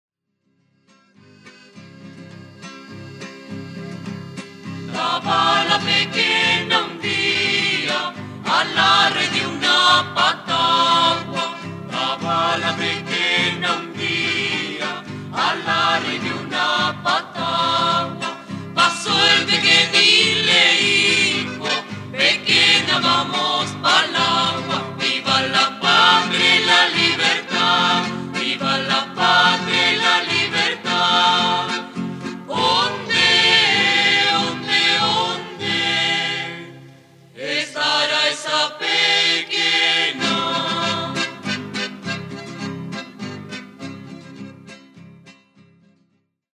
Recopilación Gabriela Pizarro Interpreta Conjunto Millaray
Materias: Música Folklórica - Chile